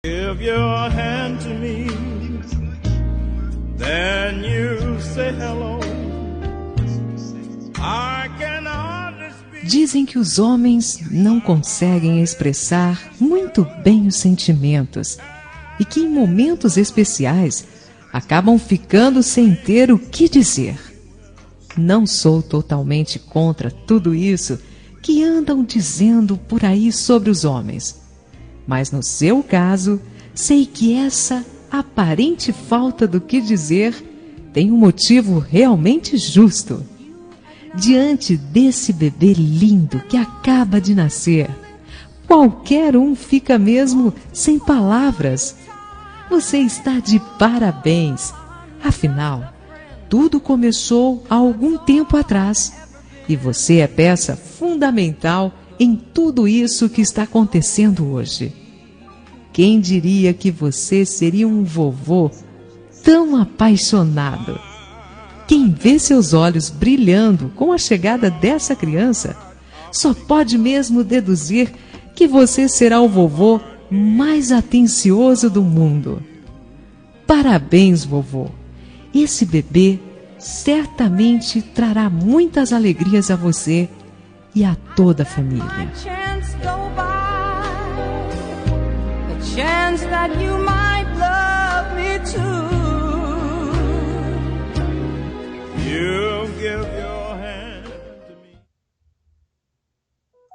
Telemensagem para Maternidade para Avó – Voz Feminina – Cód: 6632